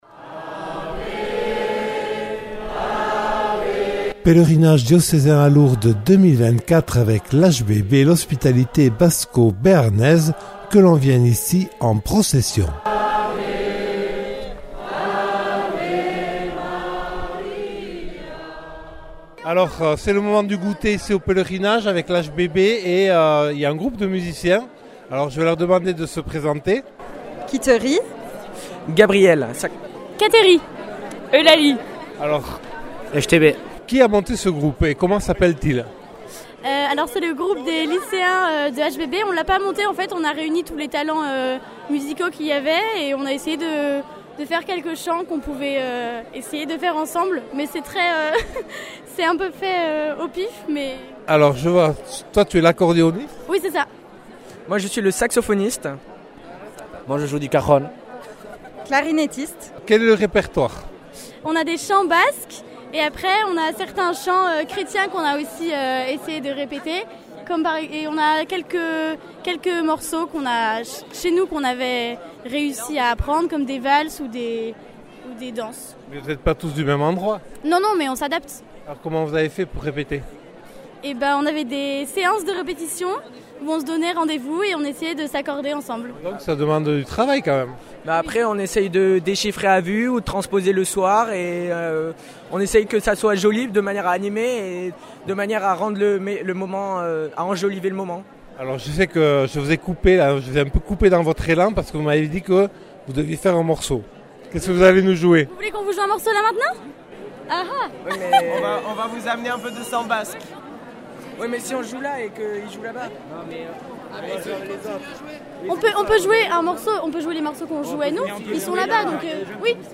Reportage 6 : les jeunes musiciens de l'HBB ; des séminaristes ; l'engagement de 34 nouveau hospitaliers.